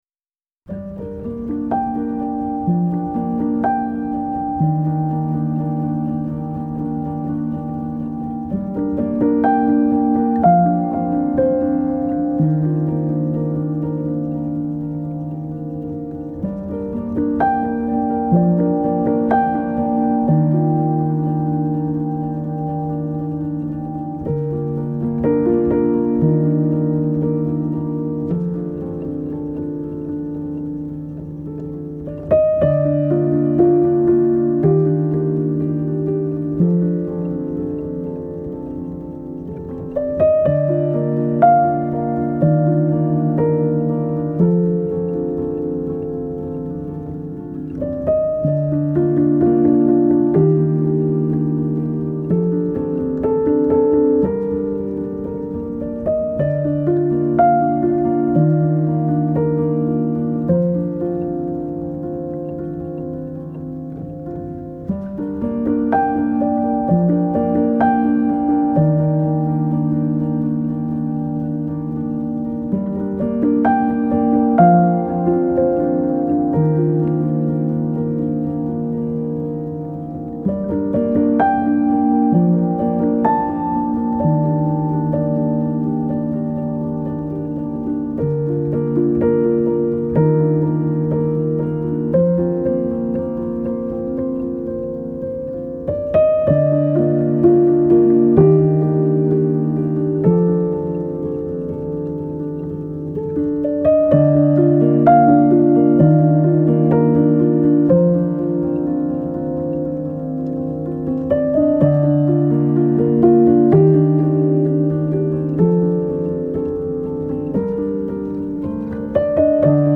آرامش بخش پیانو عصر جدید موسیقی بی کلام